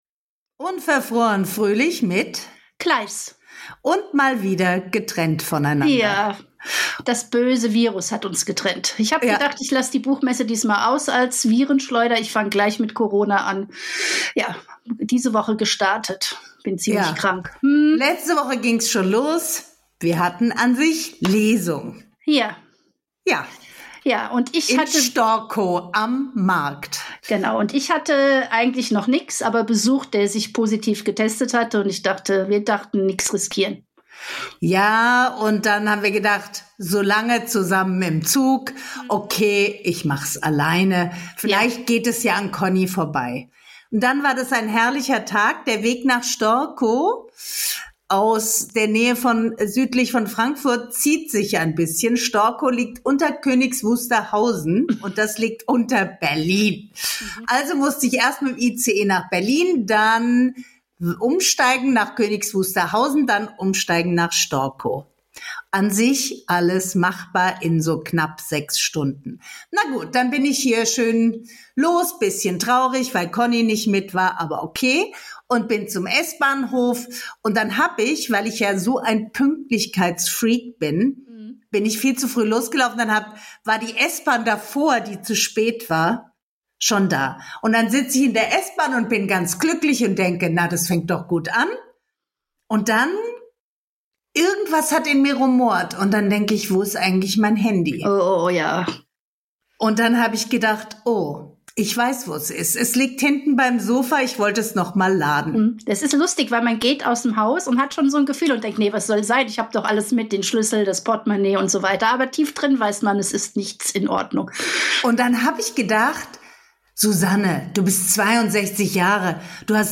die beiden Podcasterinnen